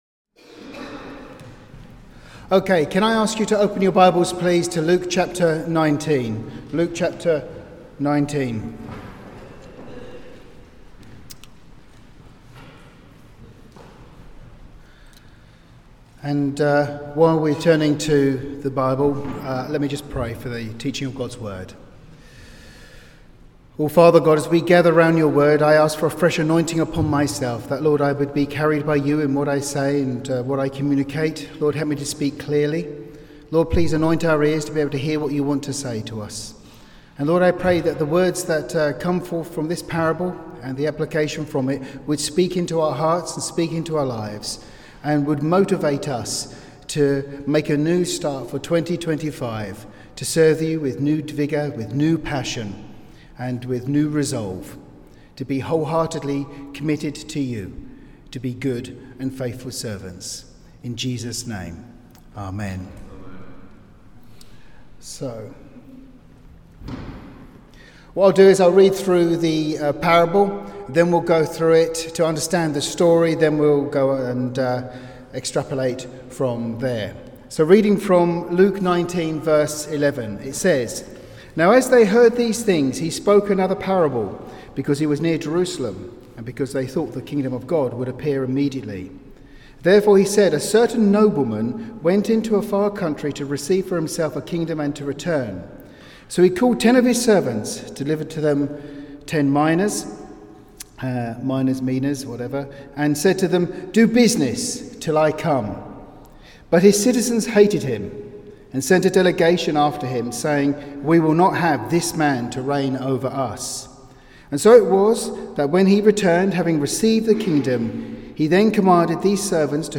Message delivered on Sunday 12th January 2025